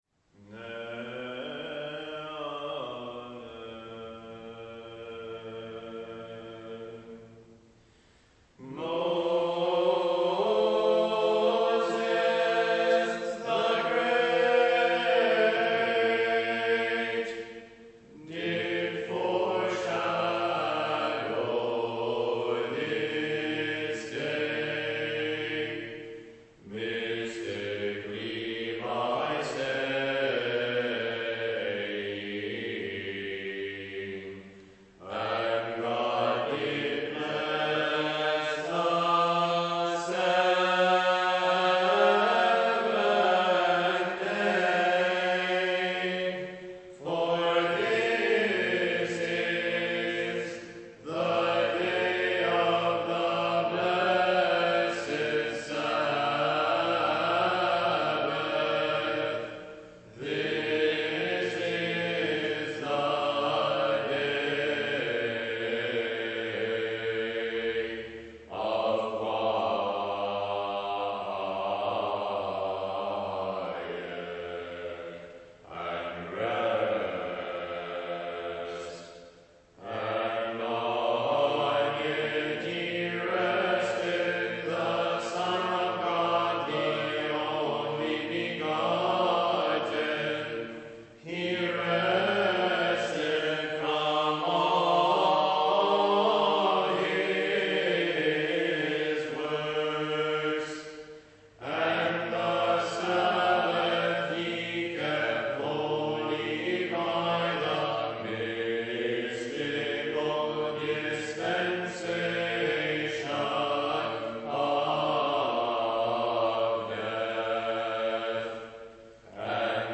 Recordings of our Byzantine Choir
Live Recordings from Services